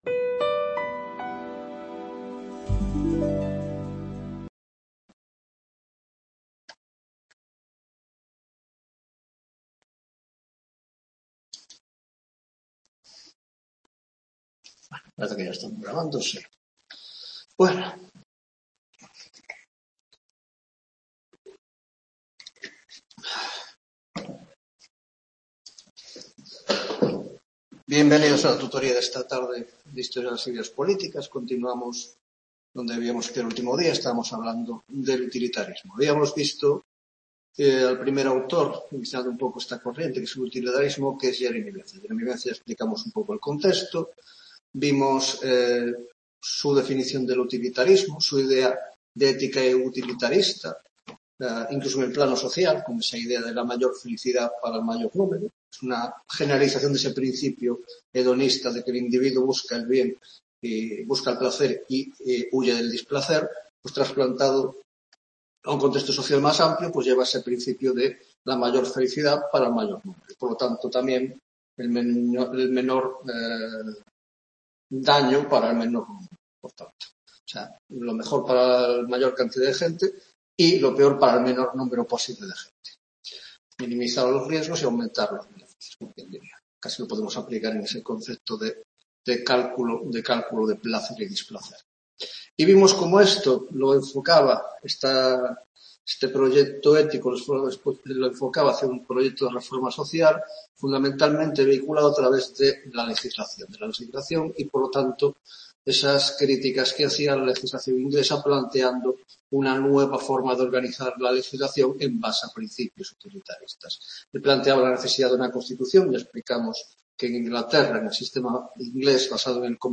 9ª Tutoría de Historia de las Ideas Políticas 2